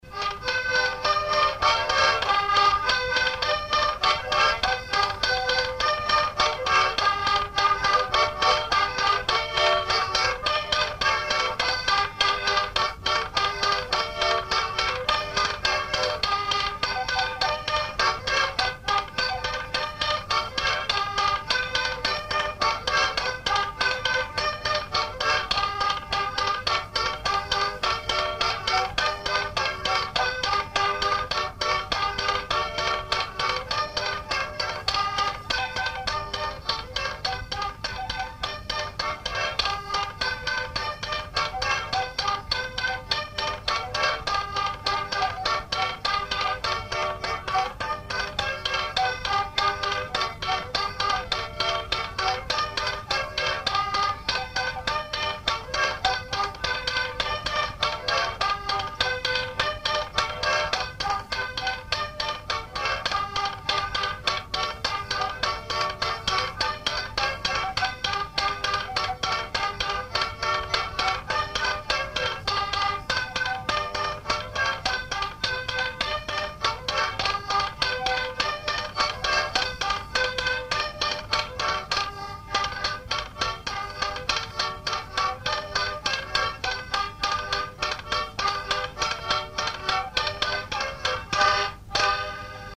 danse : polka piquée
enregistrements du Répertoire du violoneux
Pièce musicale inédite